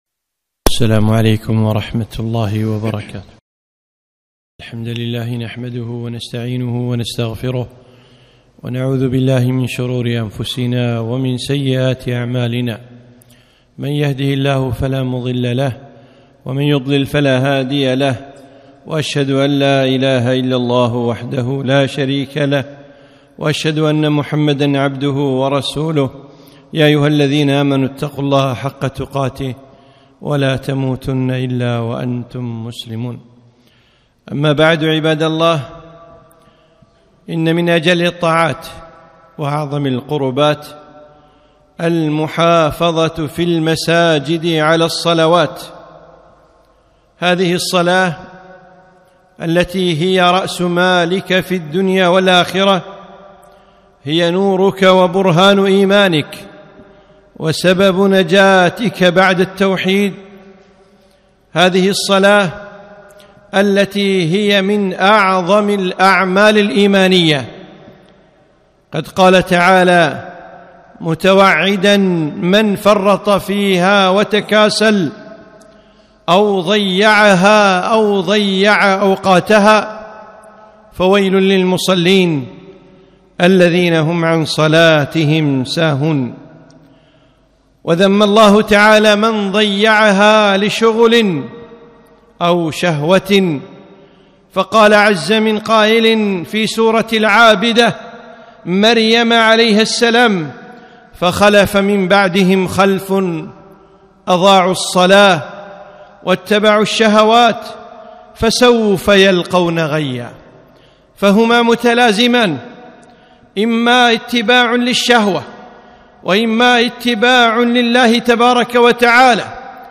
خطبة - الصلاة في المسجد